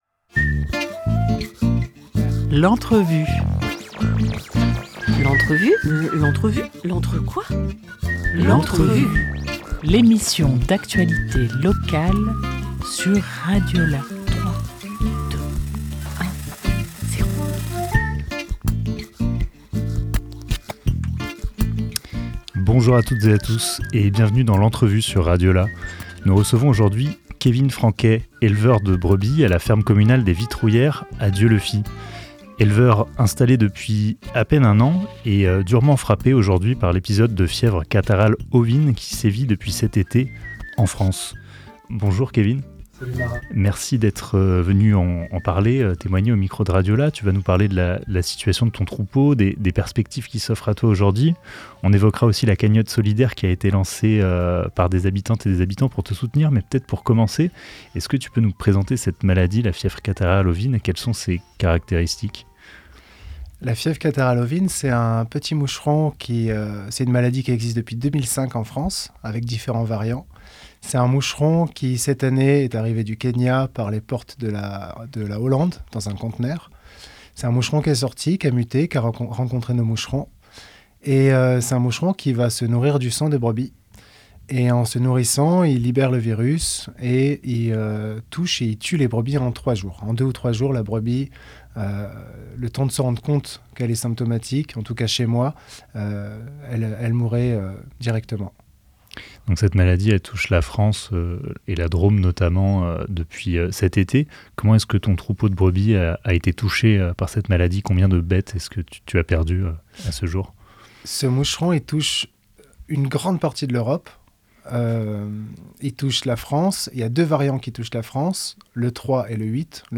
29 octobre 2024 12:39 | Interview